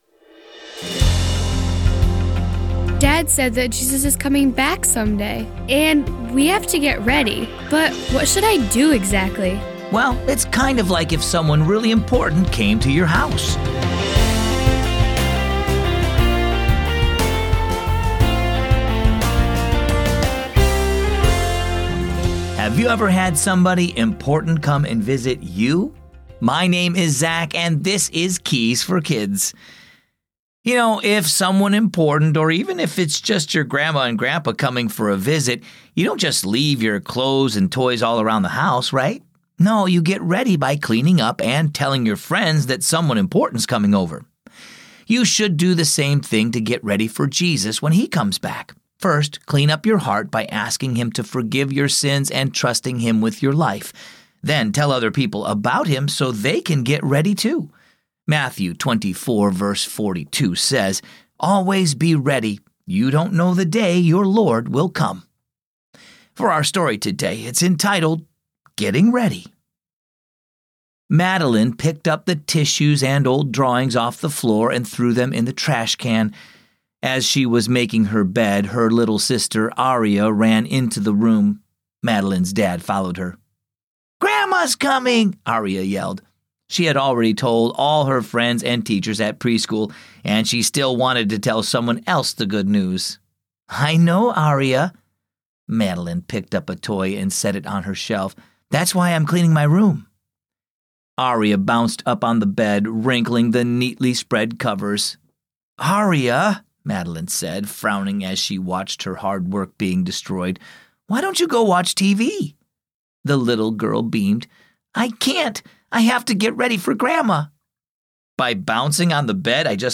Keys for Kids is a daily storytelling show based on the daily Keys for Kids children's devotional.